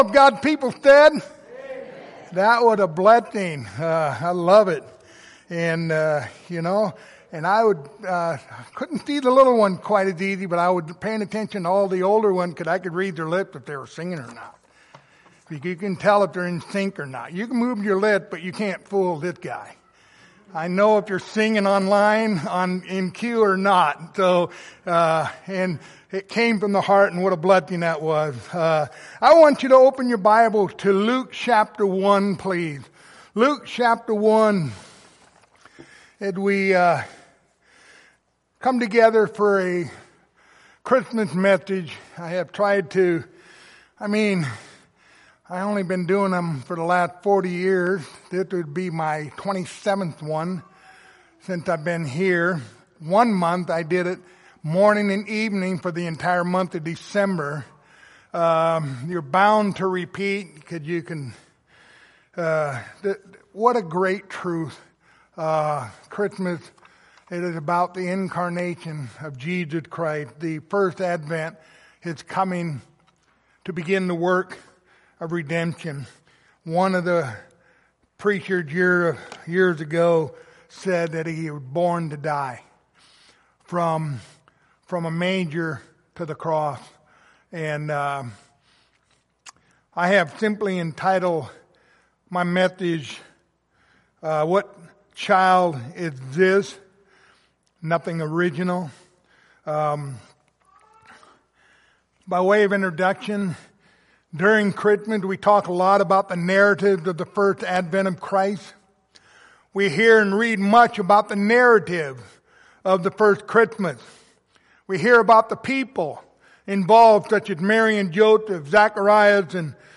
Luke 2:11 Service Type: Sunday Morning Topics